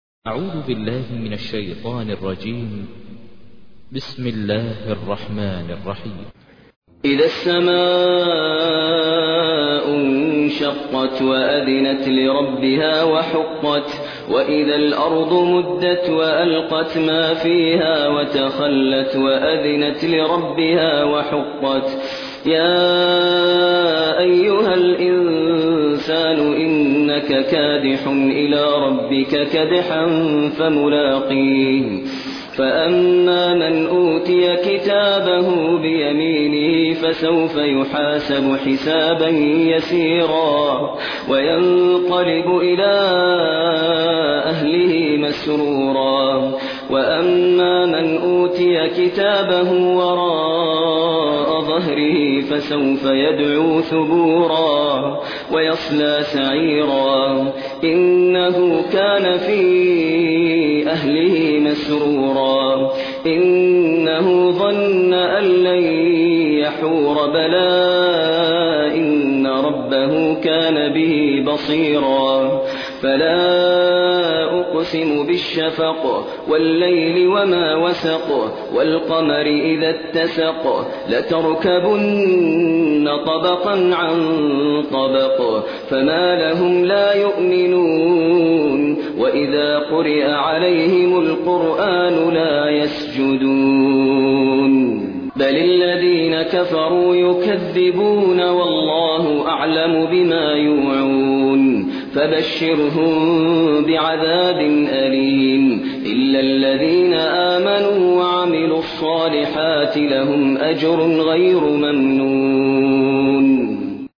تحميل : 84. سورة الانشقاق / القارئ ماهر المعيقلي / القرآن الكريم / موقع يا حسين